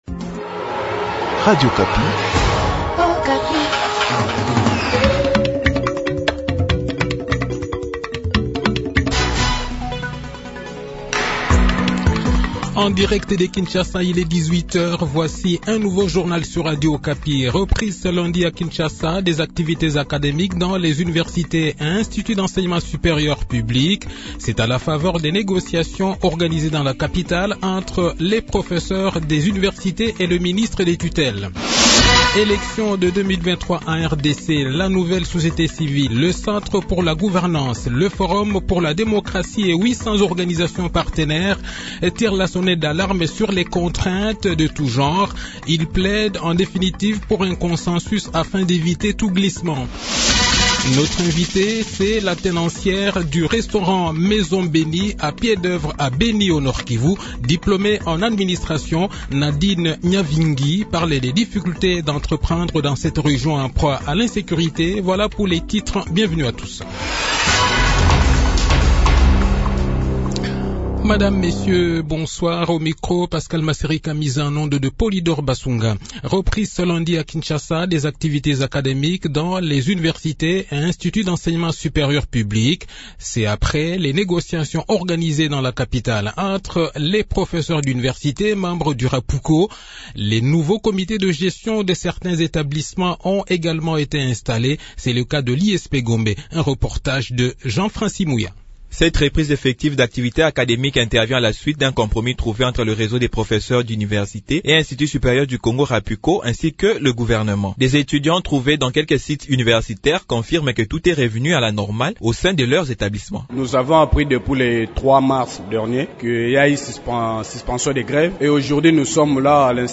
Le journal de 18 h, 7 mars 2022